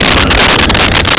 sfx_pod_ani_shift1.wav